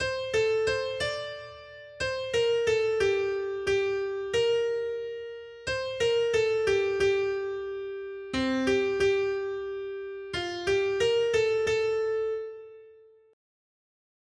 Noty Štítky, zpěvníky ol236.pdf responsoriální žalm Žaltář (Olejník) 236 Skrýt akordy R: Vstanu a půjdu k svému Otci. 1.